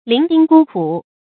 伶仃孤苦 注音： ㄌㄧㄥˊ ㄉㄧㄥ ㄍㄨ ㄎㄨˇ 讀音讀法： 意思解釋： 伶仃：孤獨，沒有依靠。